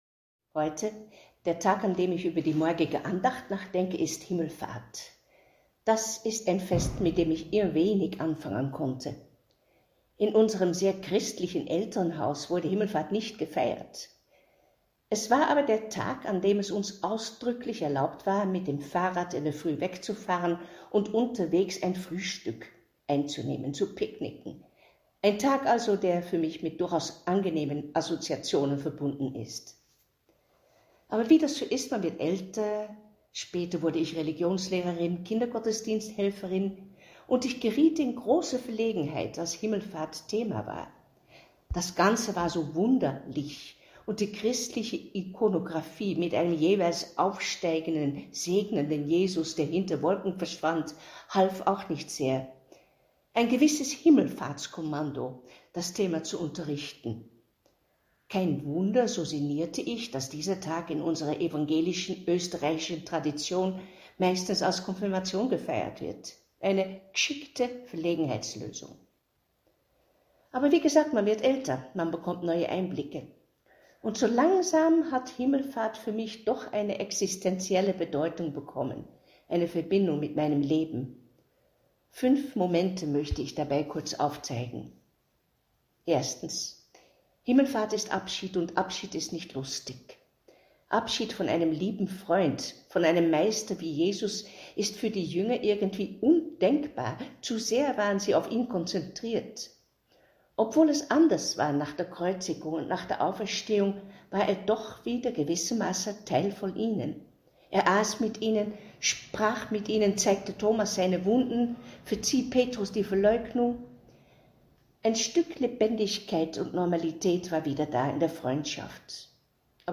Andacht „Himmelfahrt“